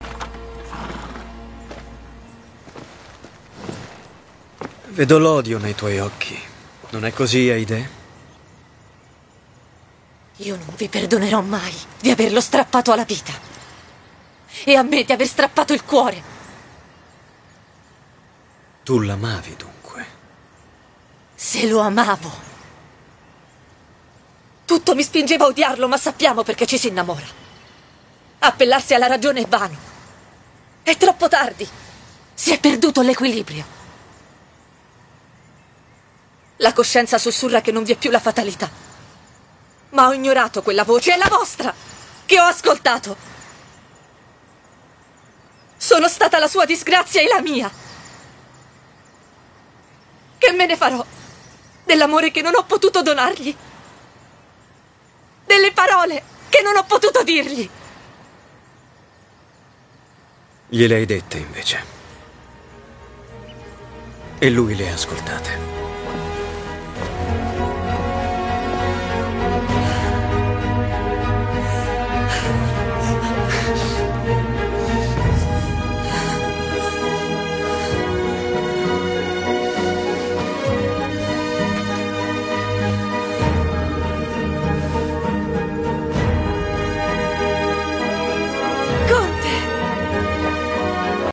nel film "Il conte di MonteCristo", in cui doppia Anamaria Vartolomei.